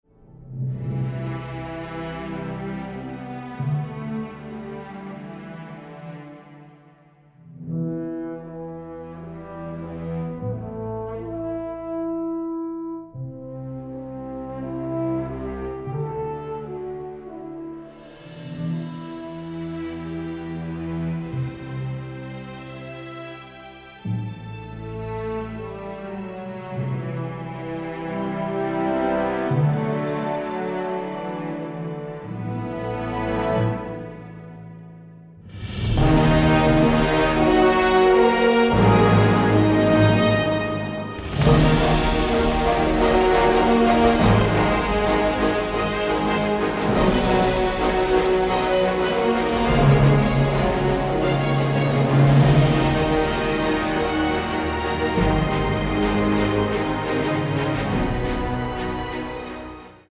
original score
full of comedic fun and heroic excitement